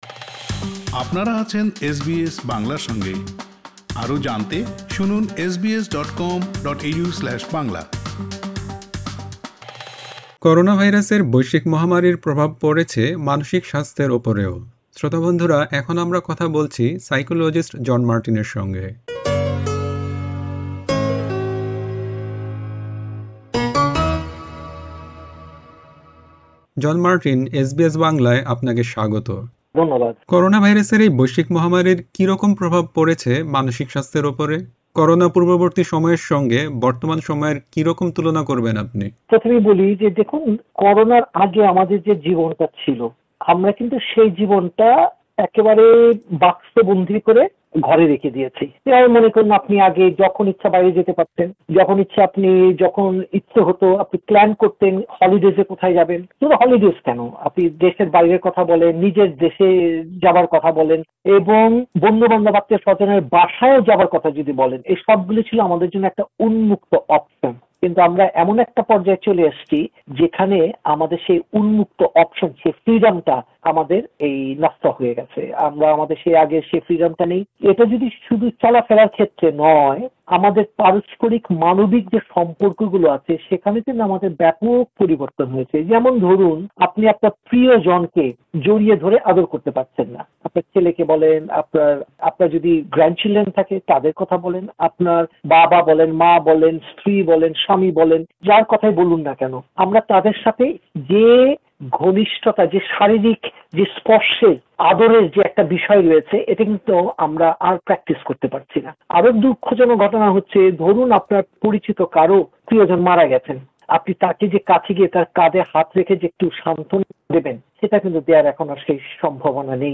এসবিএস বাংলা